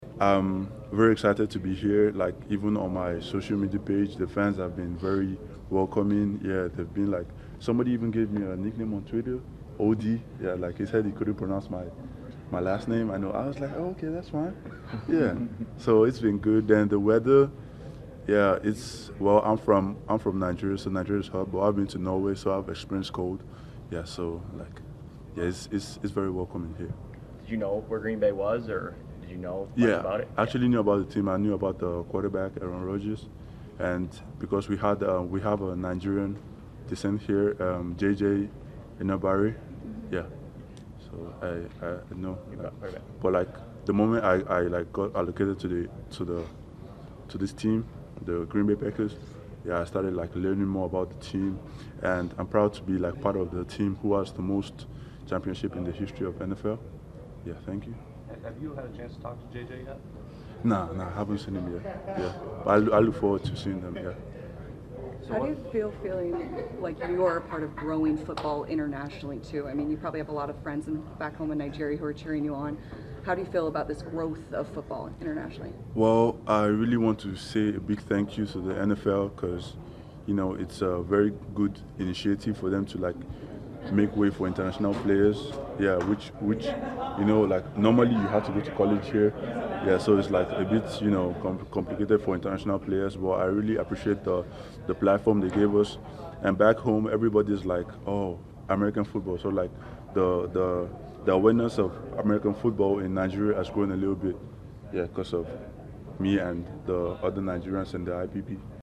He spoke with reporters about the very unique journey from Africa to Titletown USA.